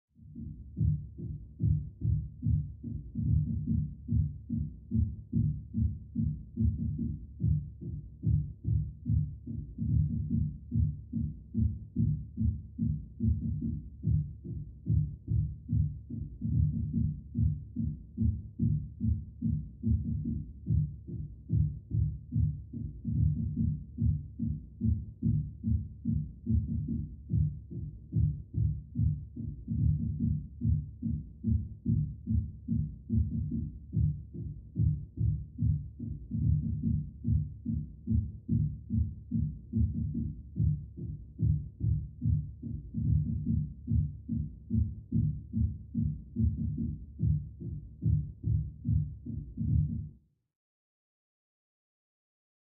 Music; Electronic Dance Beat, Through Thick Wall.